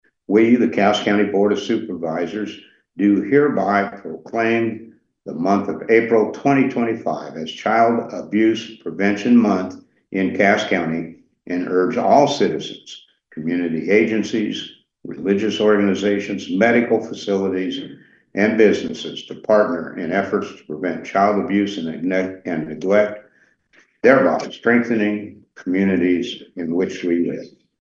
(Atlantic, Iowa) – The Cass County Board of Supervisors met this (Tuesday) morning, and following a presentation by representatives with the Child Abuse Prevention Council, declared April to be Child Abuse Awareness Month. Here's a brief summary of the proclamation from Board Chair Steve Baier…